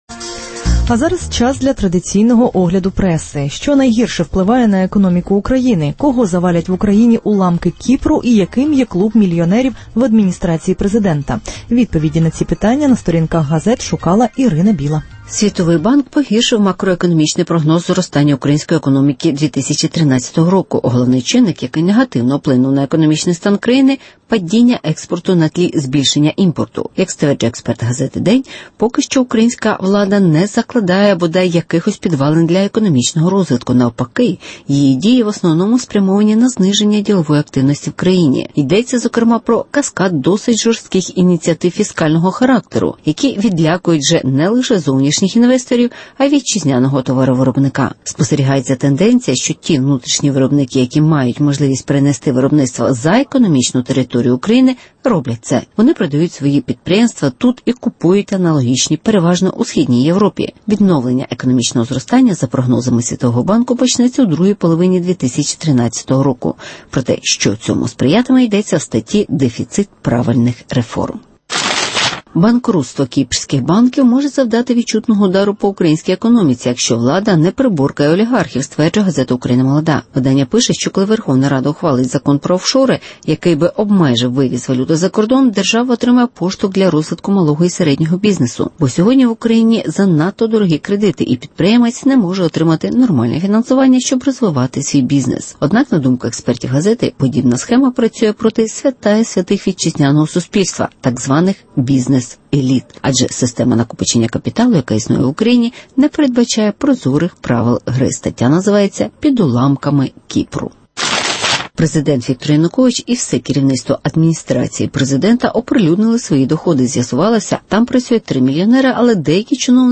Мільйонери з адміністрації президента отримують матеріальну допомогу (огляд преси)